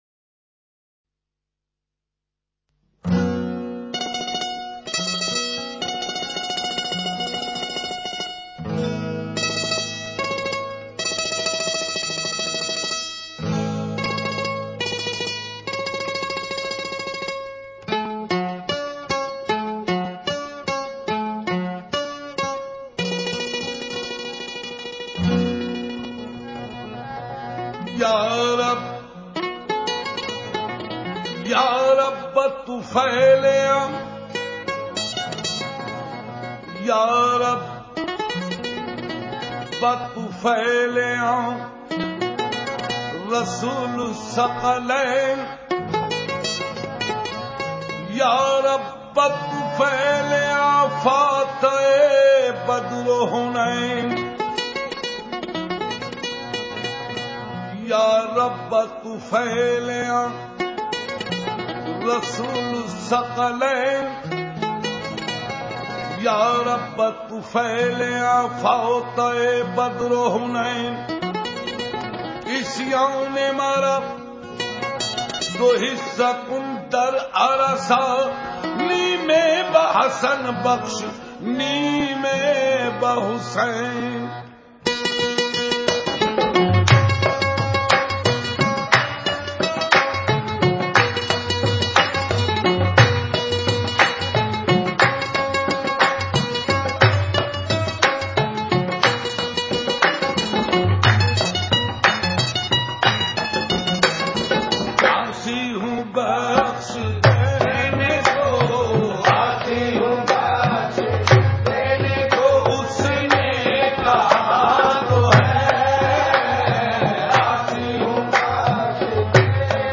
Qawwali